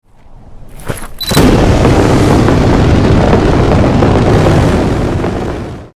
Play, download and share DoorSlam original sound button!!!!